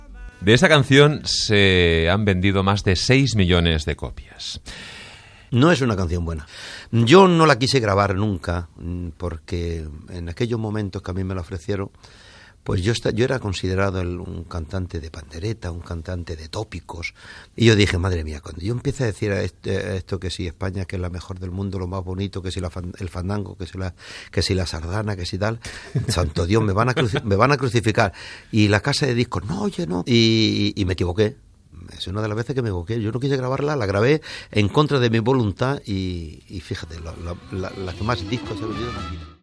El cantant Manolo Escobar fa autocrítica de la caçó "Y viva España"
Entreteniment